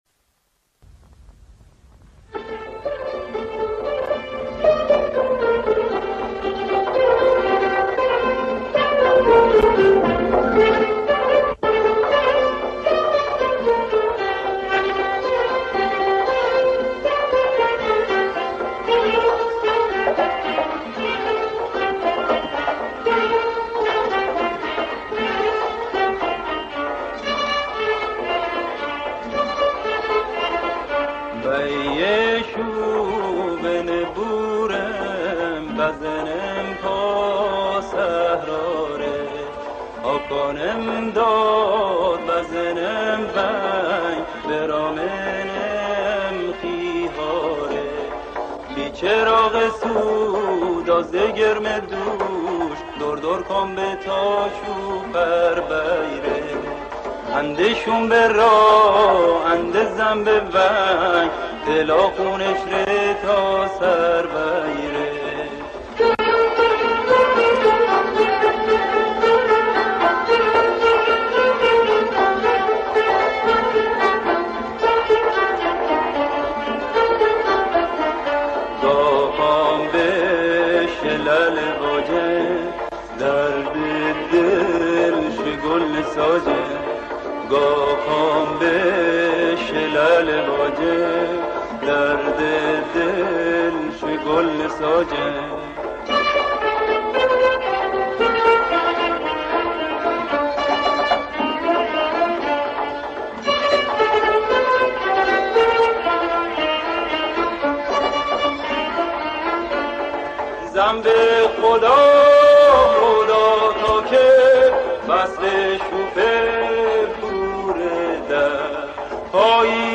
ترانه زیبای مازندرانی